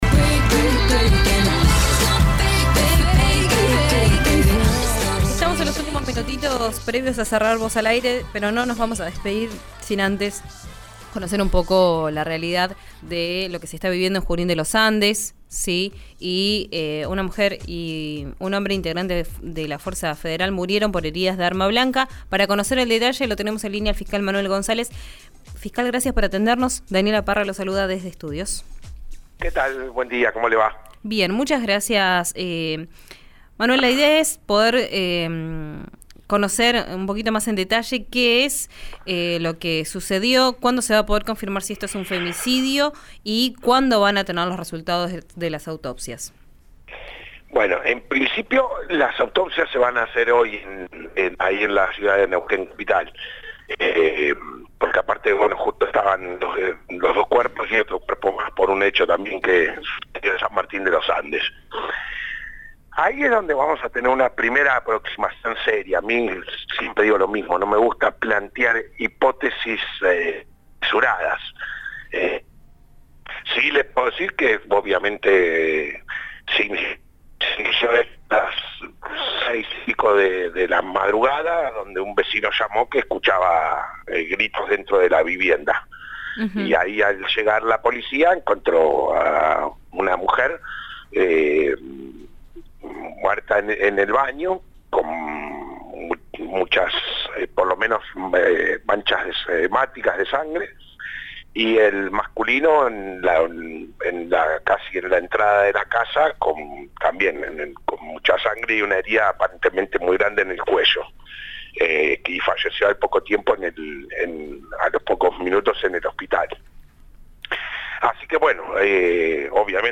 El fiscal Manuel González, dio detalles sobre el crimen y la investigación en RÍO NEGRO RADIO.
Escuchá al fiscal Manuel González, en RÍO NEGRO RADIO: